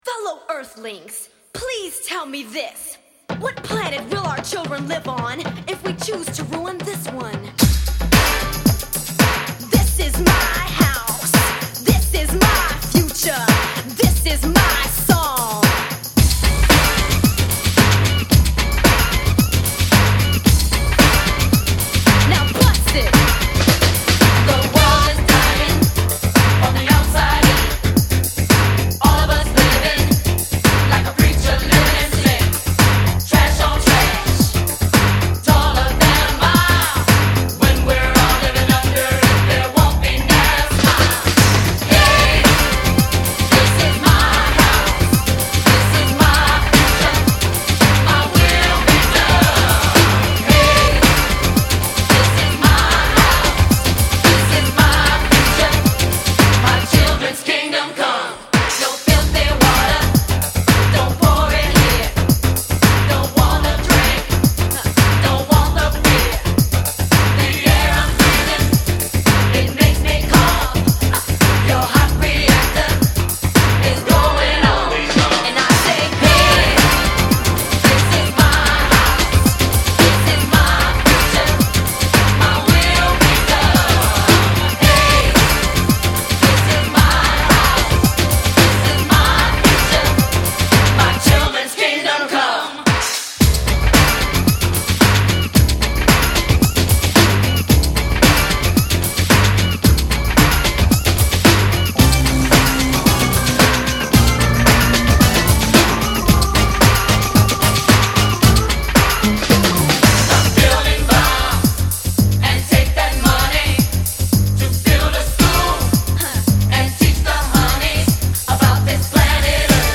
pop R&B